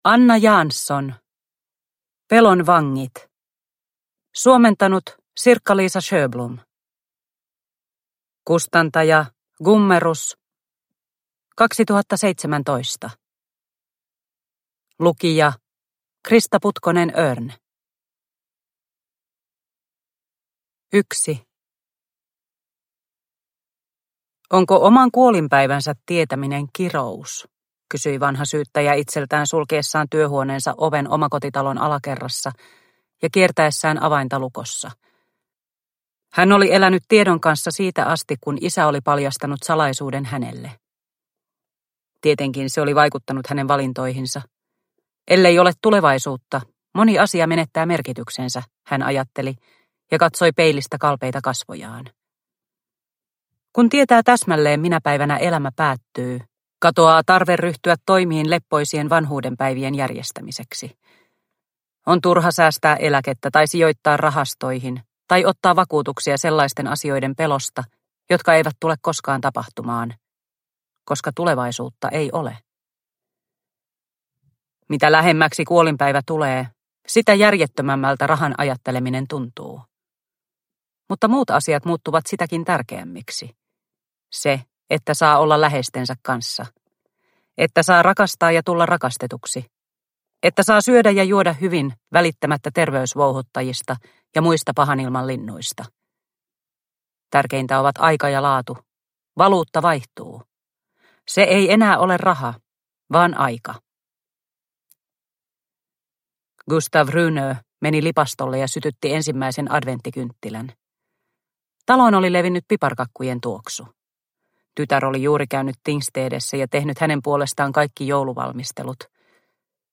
Pelon vangit – Ljudbok – Laddas ner